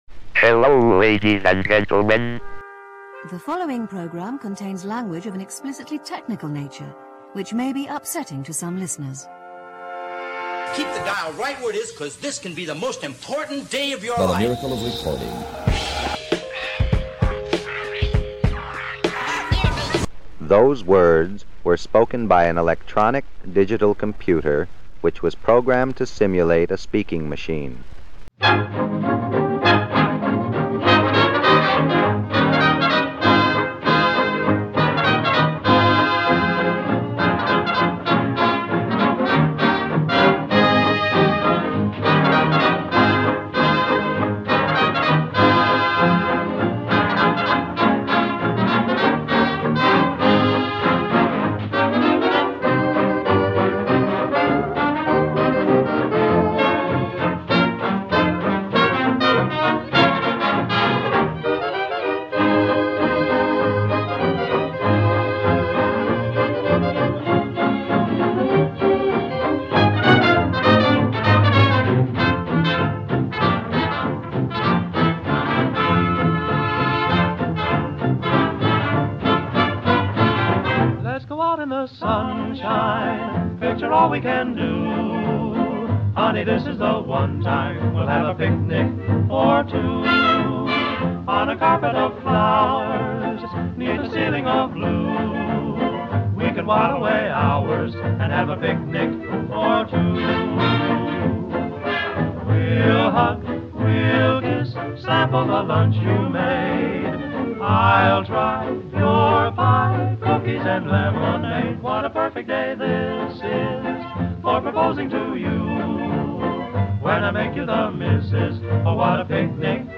It's time for a full hour of audio nonsense from me. There's a bunch of music in there, some radio, and an interview